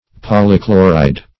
Search Result for " polychloride" : The Collaborative International Dictionary of English v.0.48: Polychloride \Pol`y*chlo"ride\, n. [Poly- + chloride.]